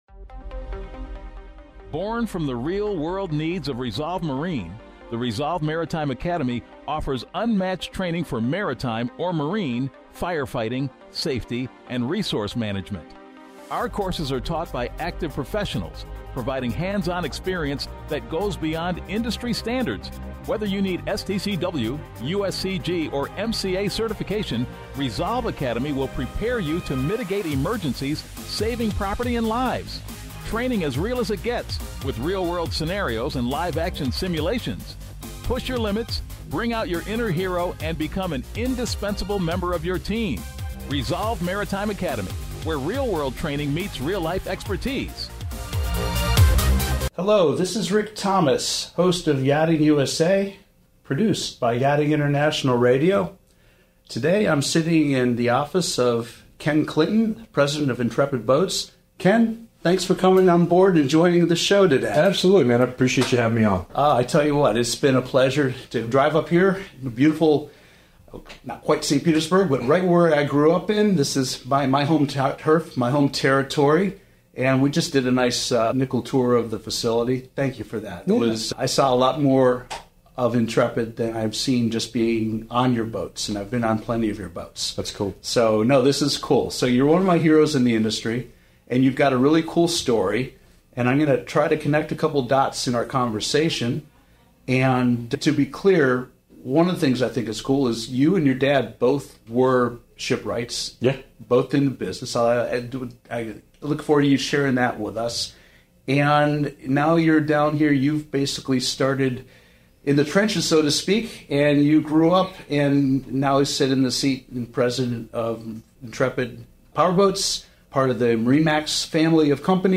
Learn about Intrepid Powerboats' unique features, high-quality craftsmanship, and custom-built designs that set them apart in the yachting world. In this exclusive interview